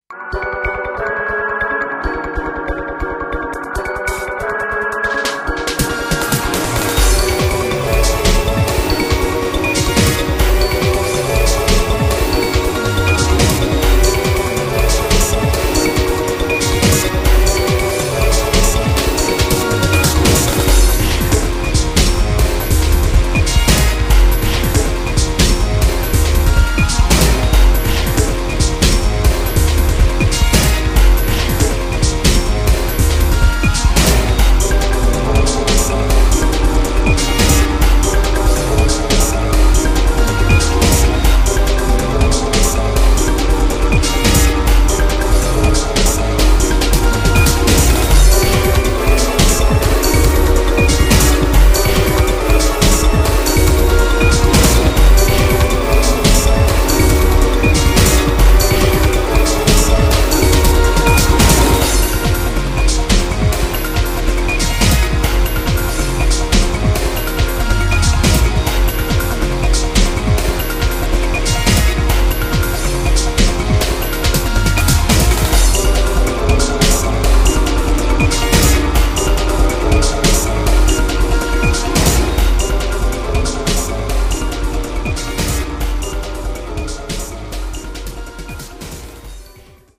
using a computer (number: End of the Dying World)